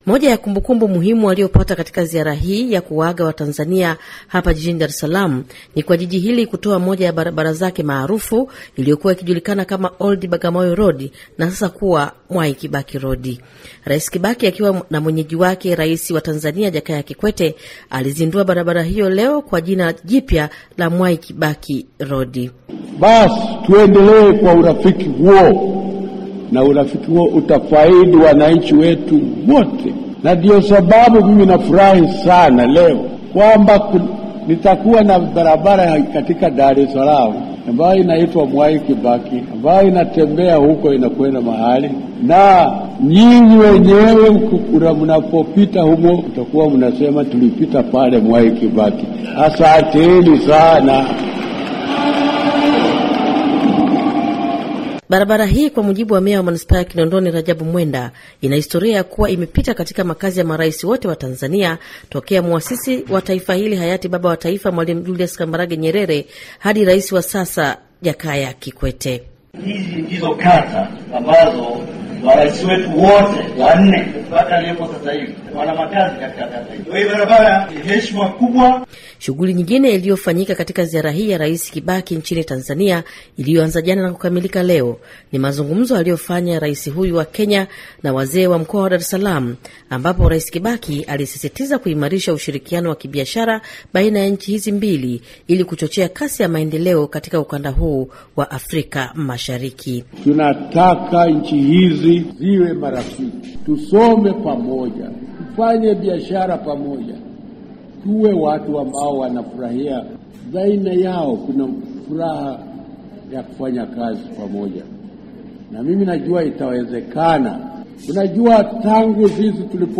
Ripoti ya